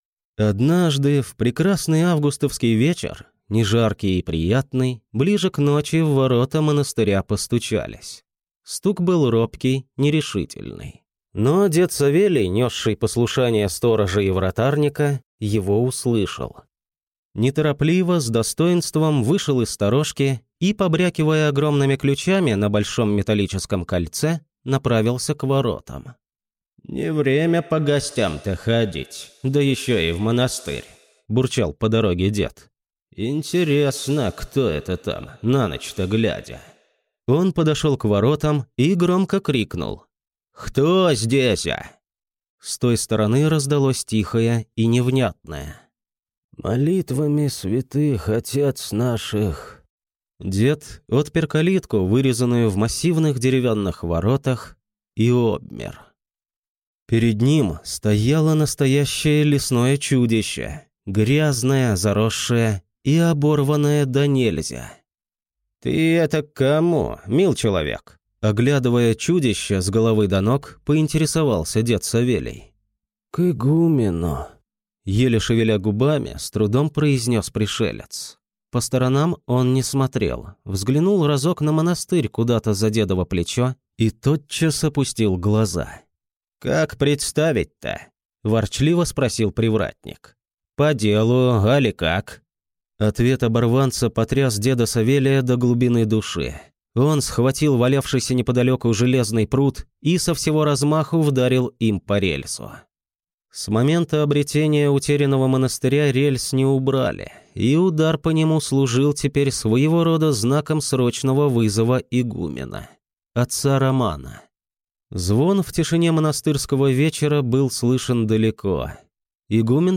Аудиокнига Ведьмины болота 2. Последняя жертва Шашургу | Библиотека аудиокниг
Прослушать и бесплатно скачать фрагмент аудиокниги